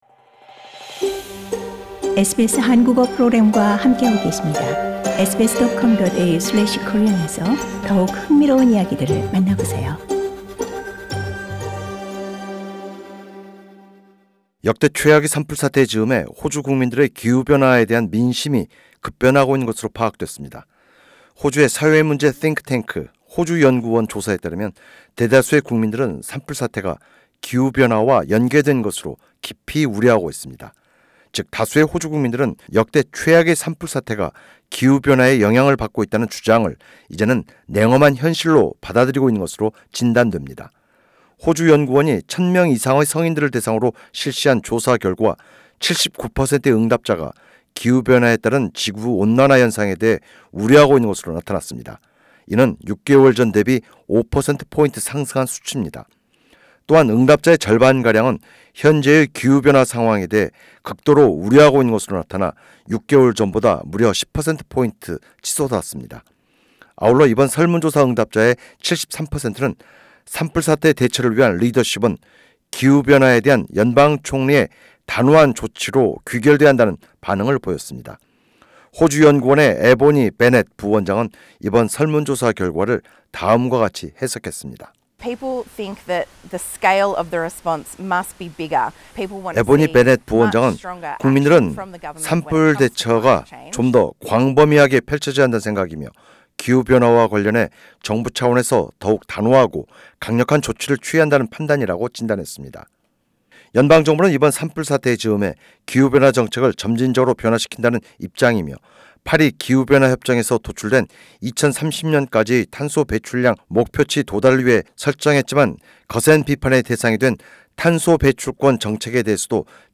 [상단의 팟캐스트를 통해 오디오 뉴스를 접하실 수 있습니다.]